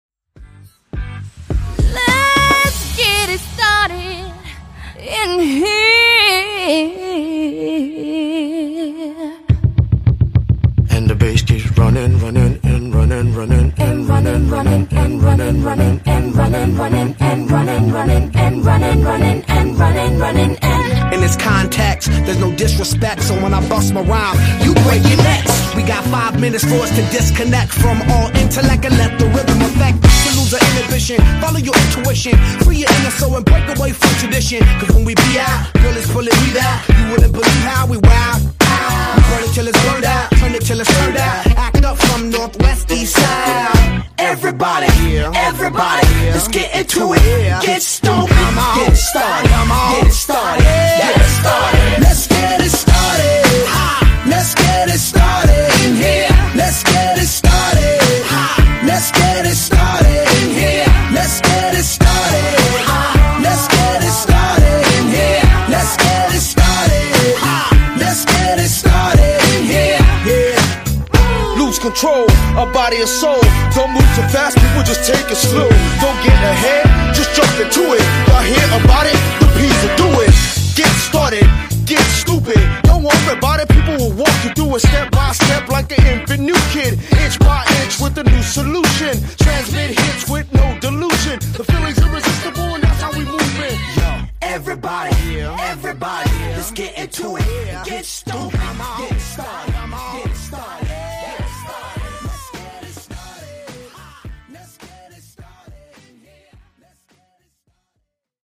Genre: RE-DRUM Version: Clean BPM: 135 Time